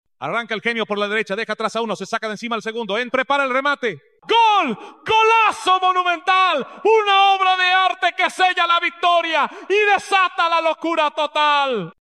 再比如，充满激情的西班牙体育赛事解说员：